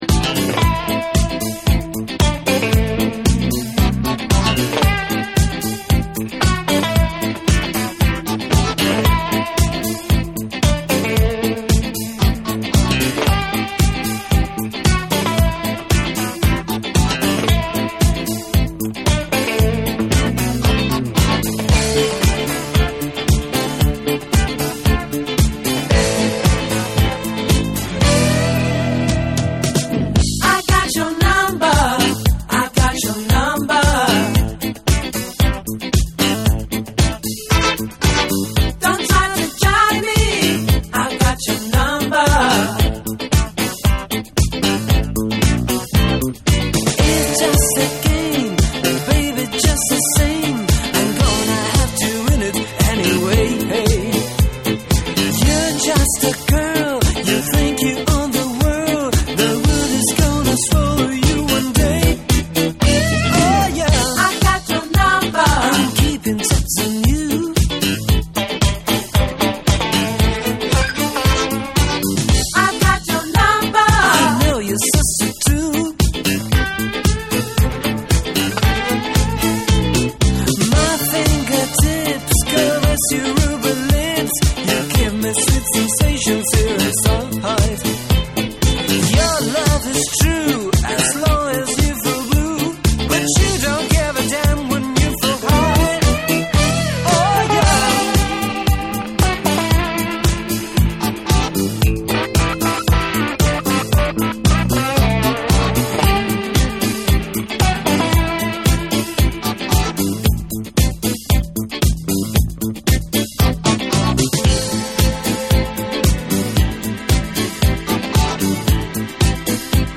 キャッチーなメロディーとグルーヴィーな演奏で展開する
DANCE CLASSICS / DISCO